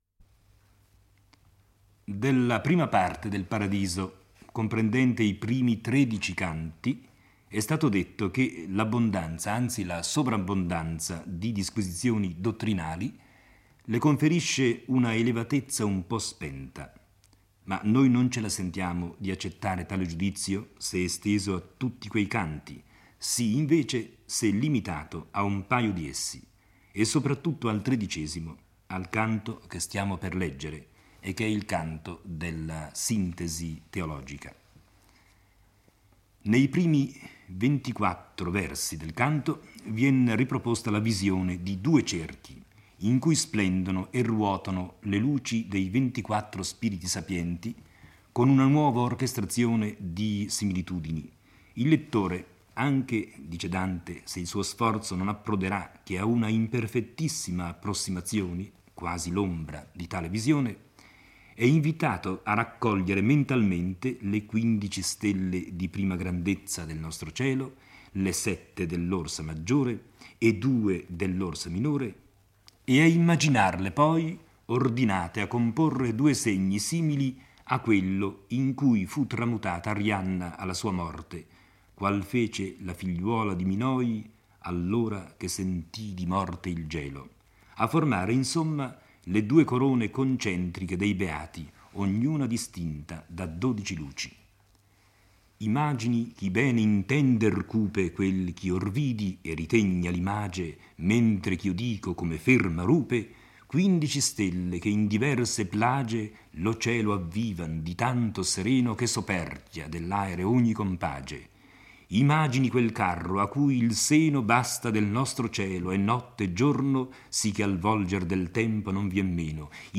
legge e commenta il XIII canto del Paradiso